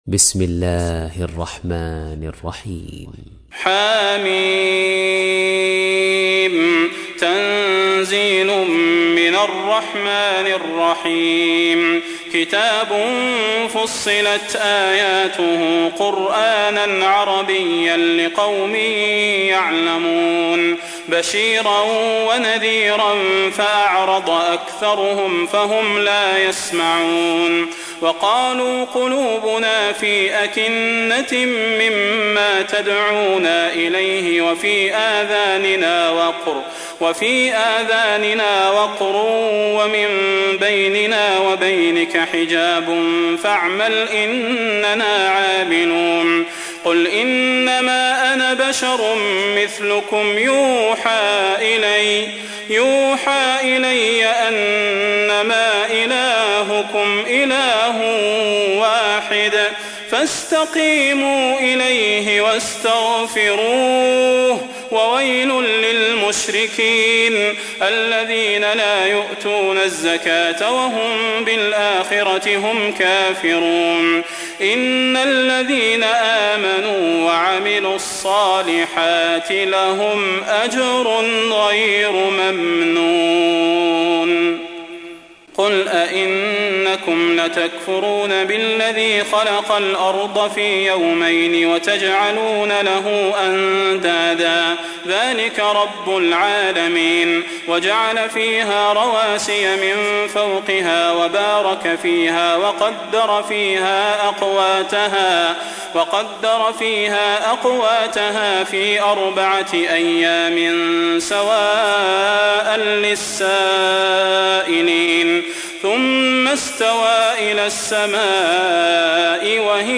تحميل : 41. سورة فصلت / القارئ صلاح البدير / القرآن الكريم / موقع يا حسين